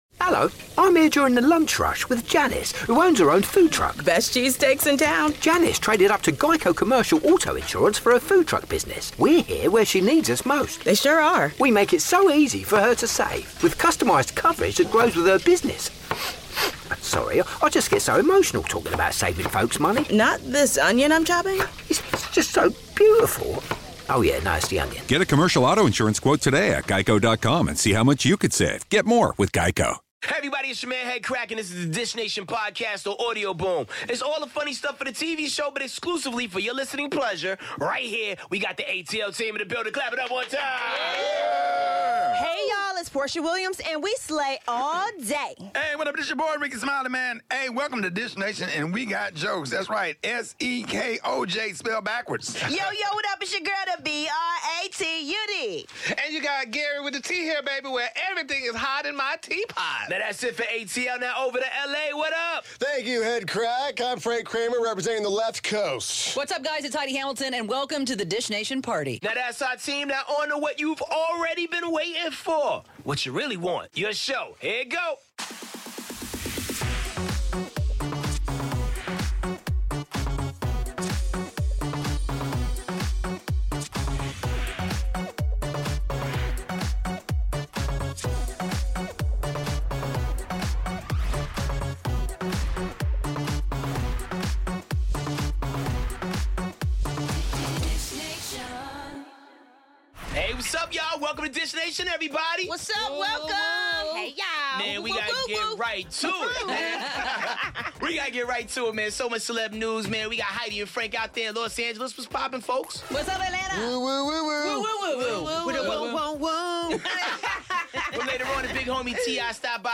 Bethenny Frankel gets her groove back 💁🏻‍♀ Bruno Mars is serenaded on his birthday 🎶 And T.I. is in studio dishing it all!